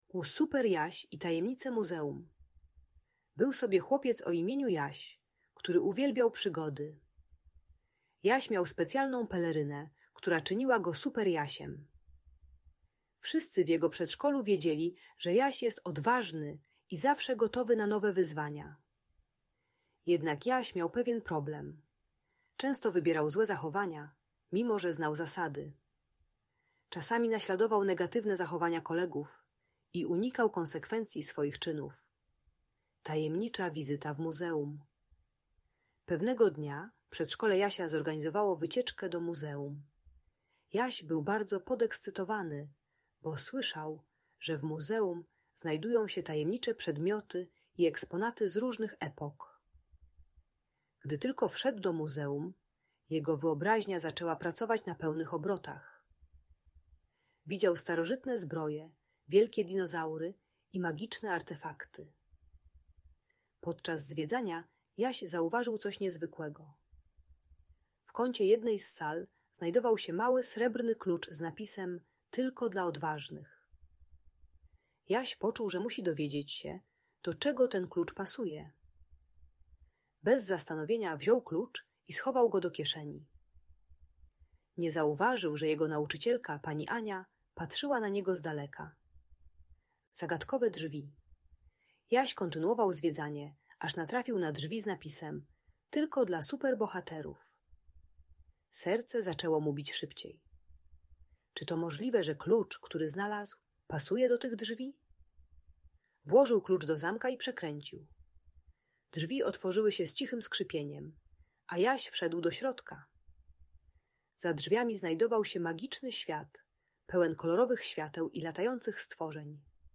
Przygody Super Jasia - Opowieść o Muzeum i Bohaterstwie - Audiobajka